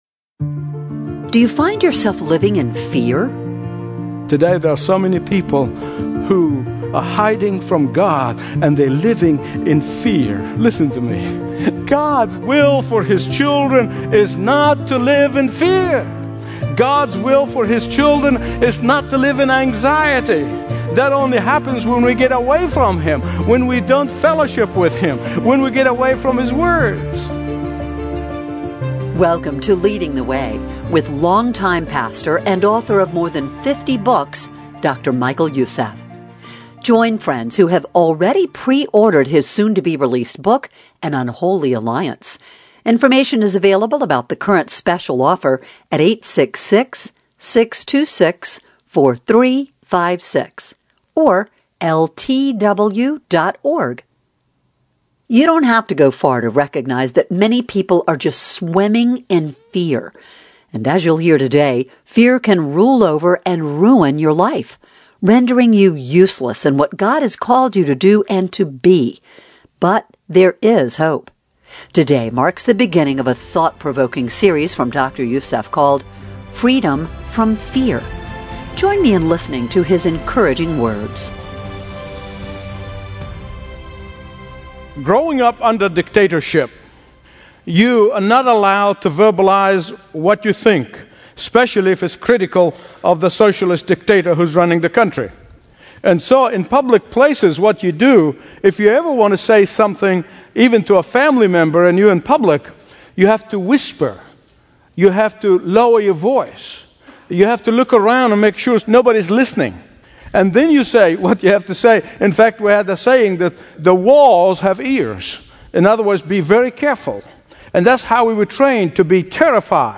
Stream Expository Bible Teaching & Understand the Bible Like Never Before.